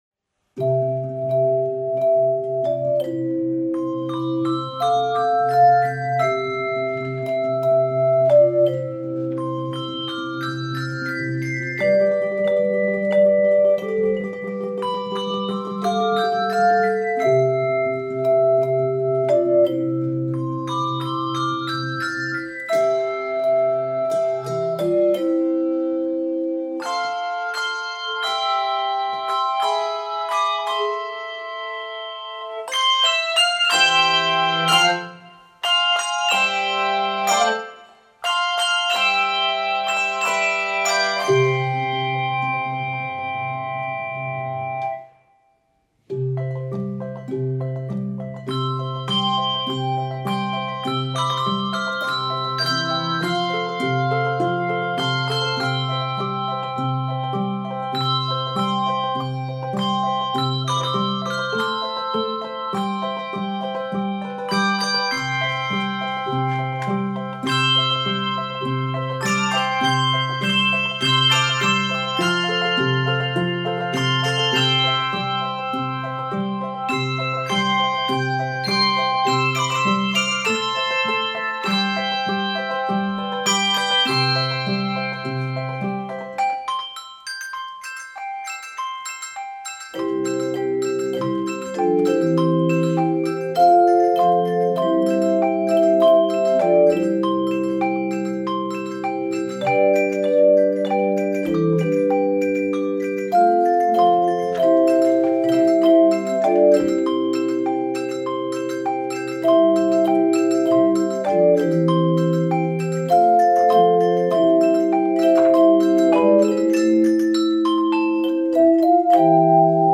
Keys of C Major and F Major.